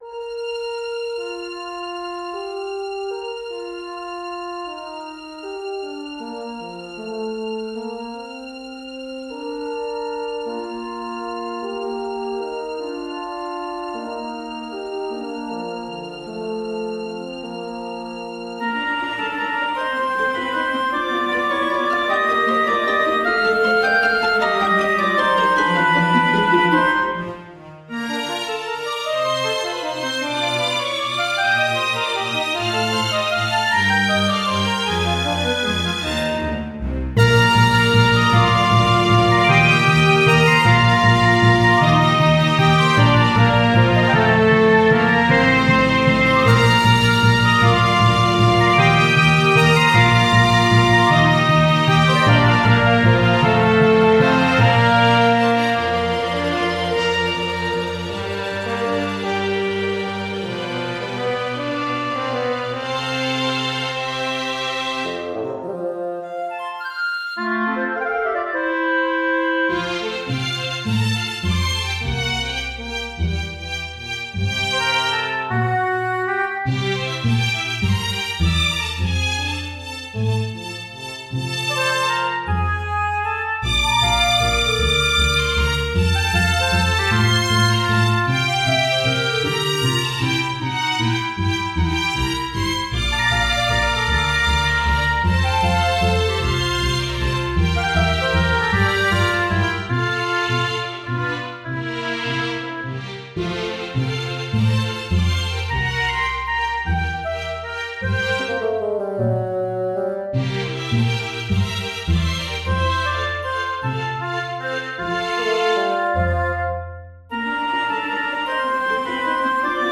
Concert Overture in Bb Major - Orchestral and Large Ensemble
This is an updated version of a concert overture I wrote in CPE style (mostly Classical Era style, with a bit of Romantic flair, and an ending that veers wildly into other territory) a few years back.